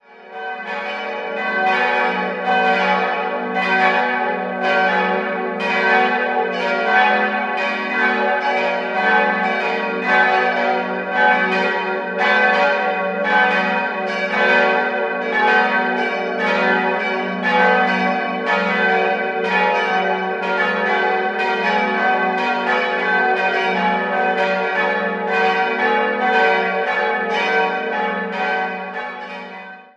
Konradglocke ges'+7 775 kg 106 cm 1965 Friedrich Wilhelm Schilling, Heidelberg Friedensglocke as'+7 500 kg 95 cm 1617 Sergius Hofmann, Liegnitz Kreuzglocke b'+7 357 kg 84 cm 1601 Urban Schober/Merten Weigel Laurentiusglocke des''+9 185 kg 67,5 cm 1775 Anton Schweiger, Glatz